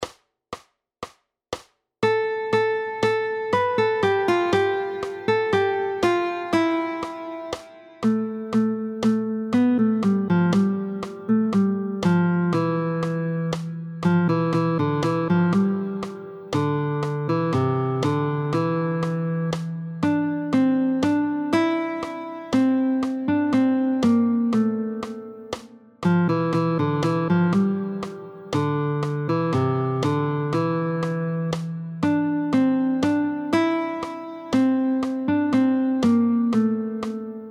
√ برای ساز گیتار | سطح آسان